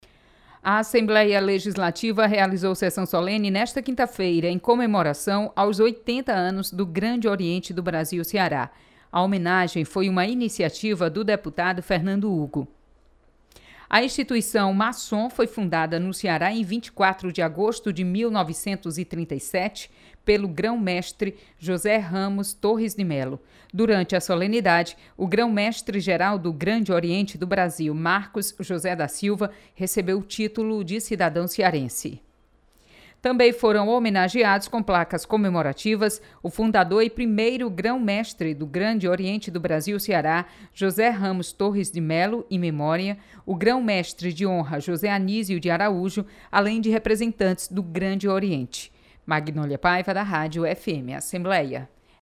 Você está aqui: Início Comunicação Rádio FM Assembleia Notícias Solenidade